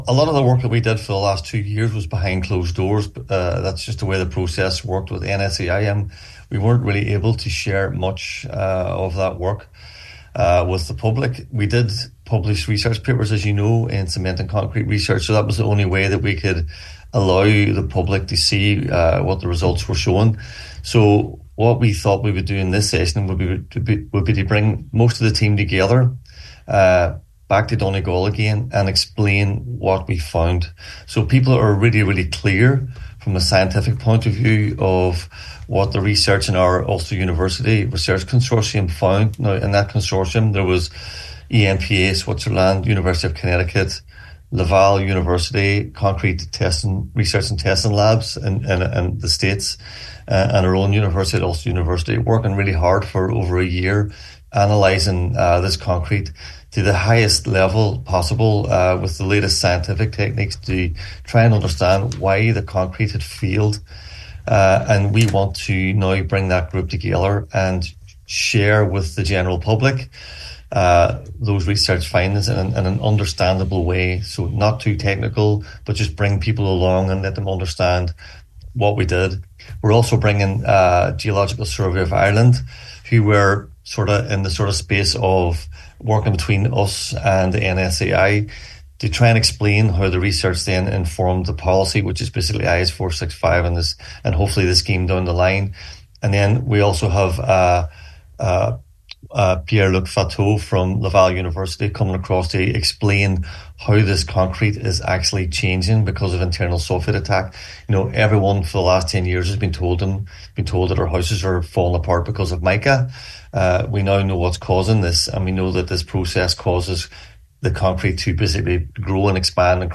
on today’s Nine til Noon Show they’ve been working with the NSAI for a long time, but this is the first time they’ve been able to go public with much of their findings…….